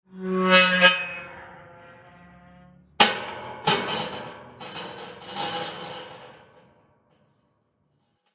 Звуки калитки
Звук захлопывающейся металлической калитки